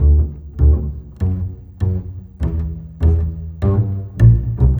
Rock-Pop 10 Bass 01.wav